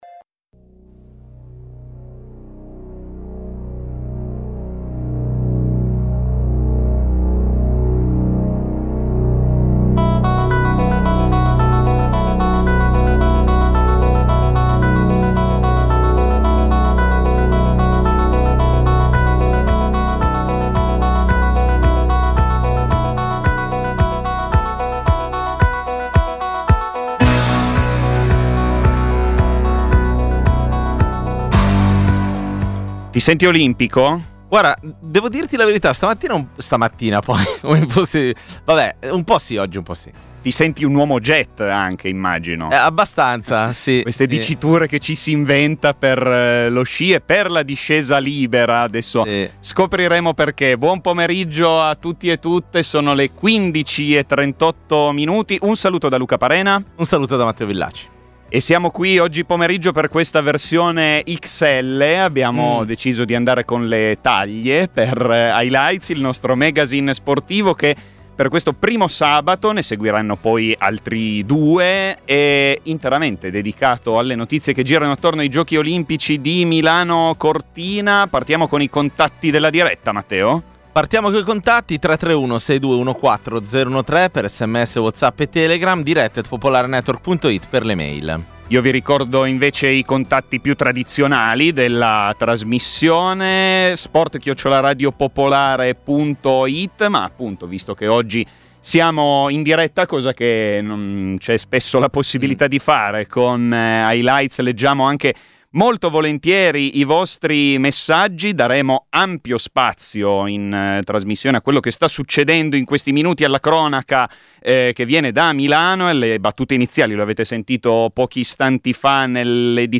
Prima di tre puntate speciali, dedicate ai Giochi olimpici invernali di Milano-Cortina. La diretta dalla manifestazione del Comitato insostenibili...